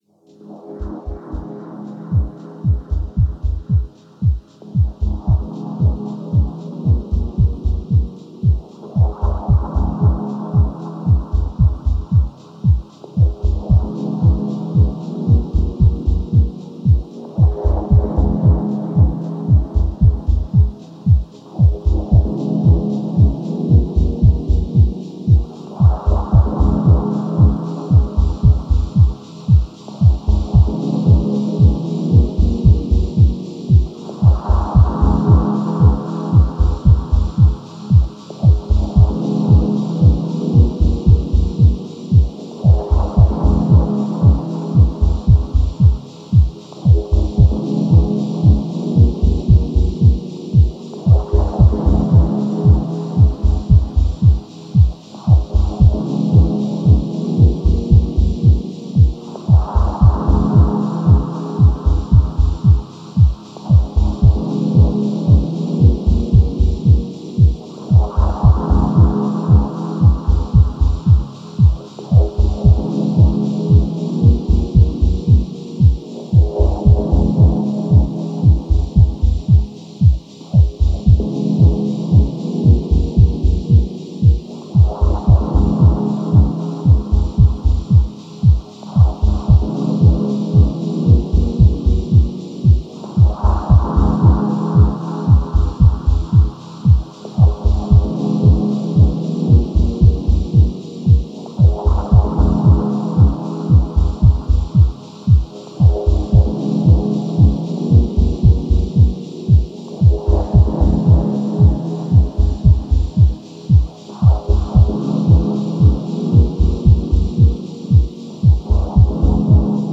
Genre: Ambient/Deep Techno/Dub Techno.